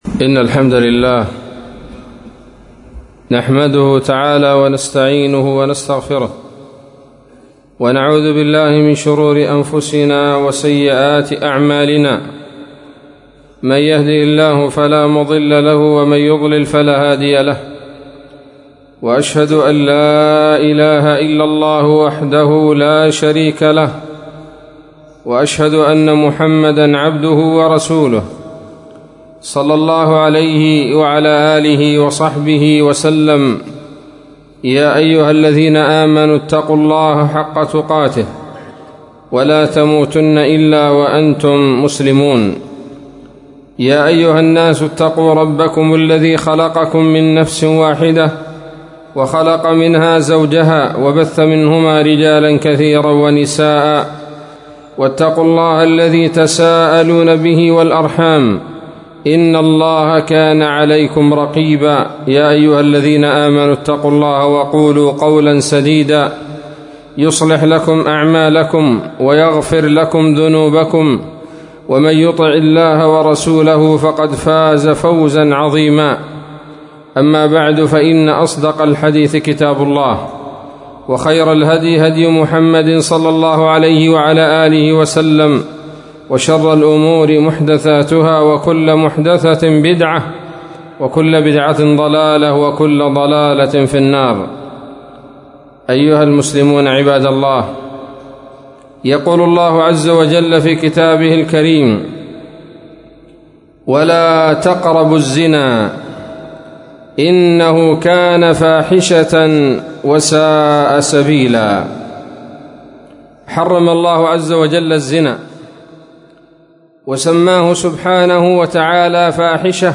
خطبة جمعة بعنوان: (( بعض الأسباب التي توصل العباد لارتكاب فاحشة الزنا )) 21 من شهر رجب 1442 هـ